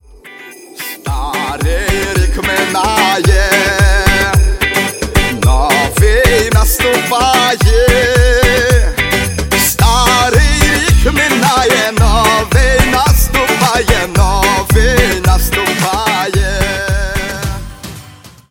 • Качество: 128, Stereo
позитивные
веселые
вдохновляющие